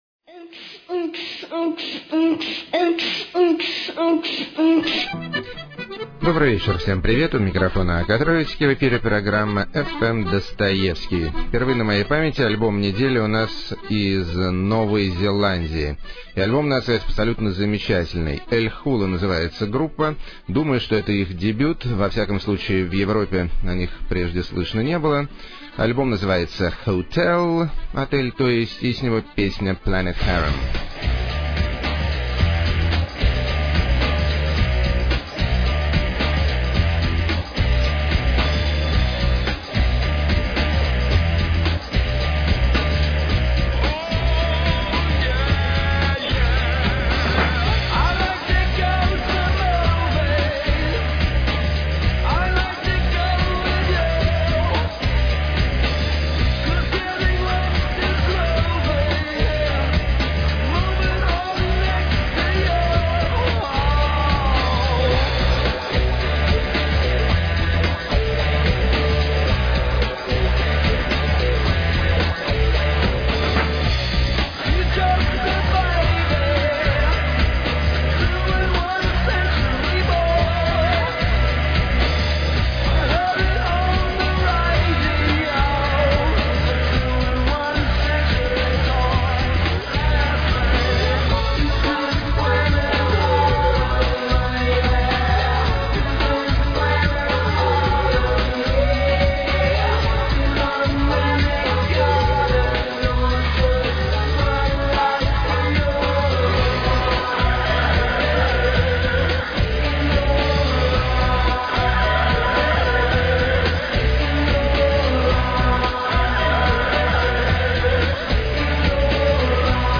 Затейливый Лоу-фай, Своеобразный Вокал.
Мягкий И Мелодичный… Афро-секс-соул.
Психоделический Нео-фолк С Феминизмом.
Трагическое Кабаре С Уклоном В Этно-шансон.